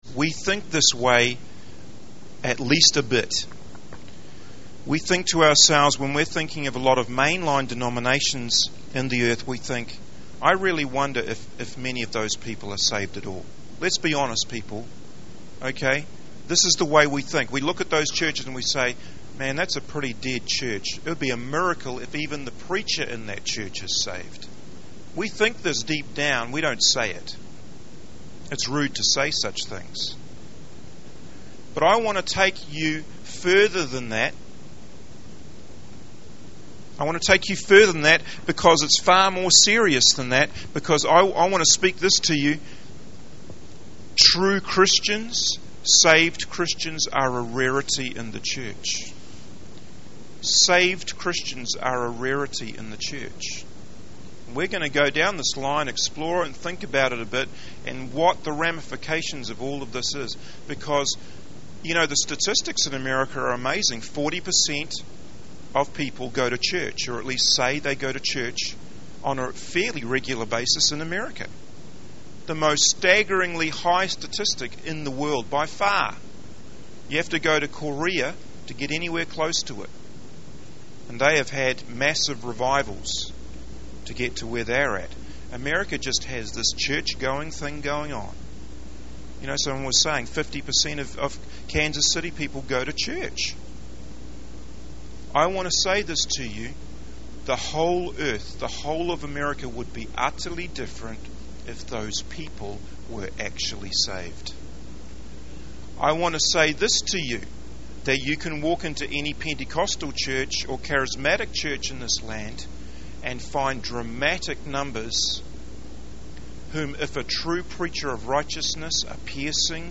In this sermon, the speaker shares a powerful story about Charles Finney, a preacher who had a profound impact on people's lives.